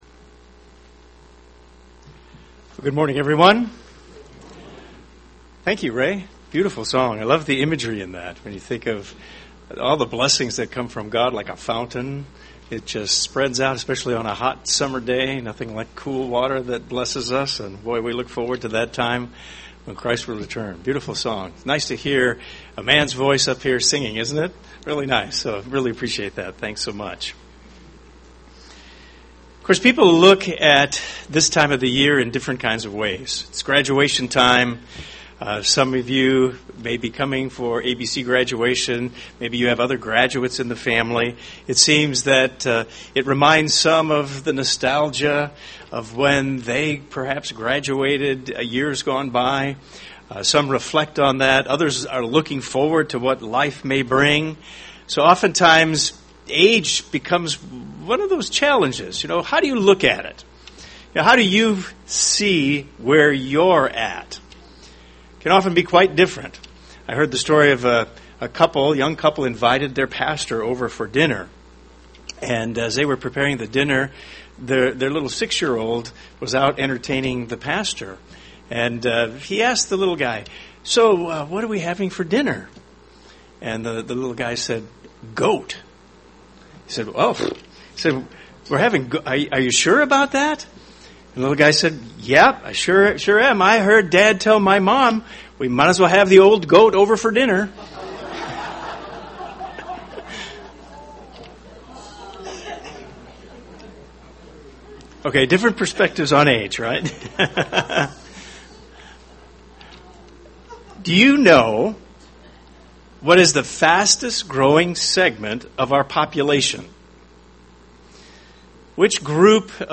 Thus sermon discusses God's perspective on aging.